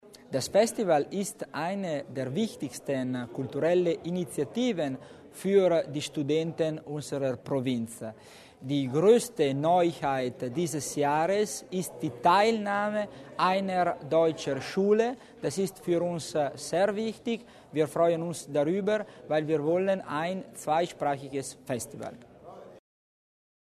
Landesrat Tommasini über die Neuigkeiten des "Festival studentesco"